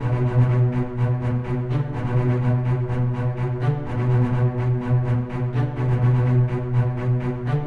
弦乐 51 125 Bpm
Tag: 125 bpm Cinematic Loops Strings Loops 1.29 MB wav Key : Unknown